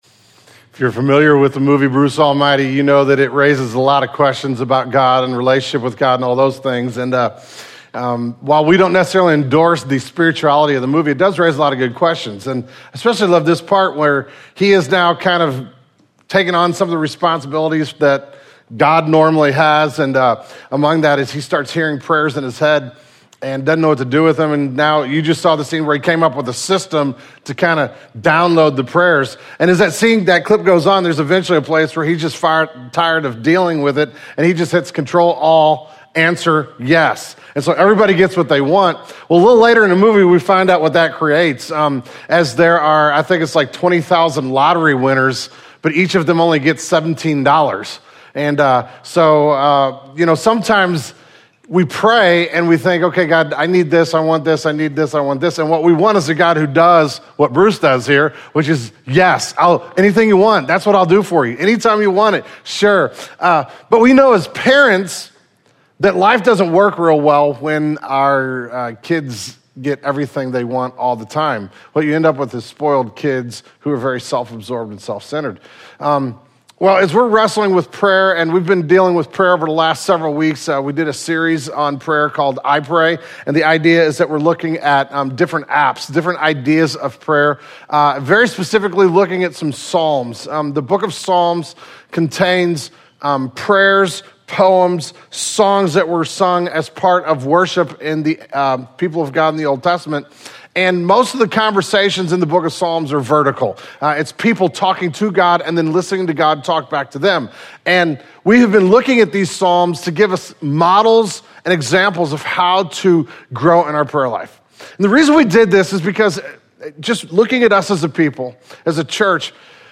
Still, showing our dependence on God by asking for his provision is an important aspect of our prayer lives. This morning, we will be preaching and finishing the iPray series by taking a look at the app of petition, or making requests to God.